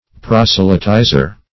Proselytizer \Pros"e*ly*ti`zer\